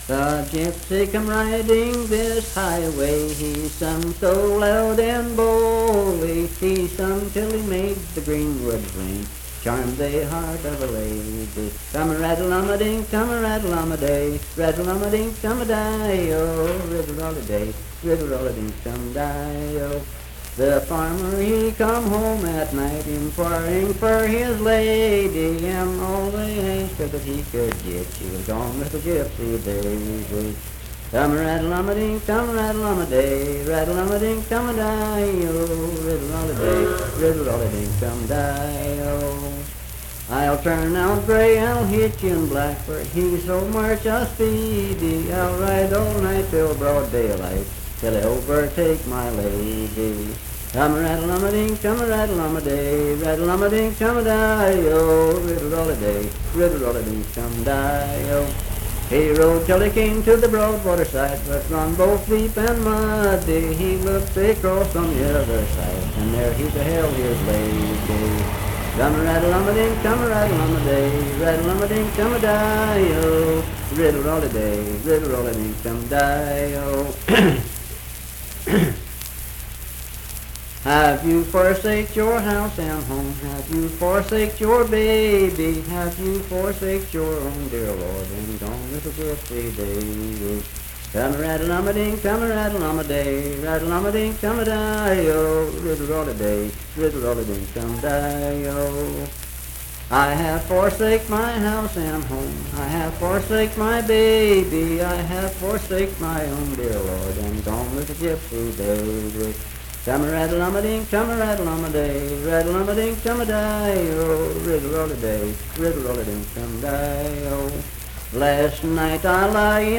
Unaccompanied vocal music performance
Verse-refrain 7(7).
Voice (sung)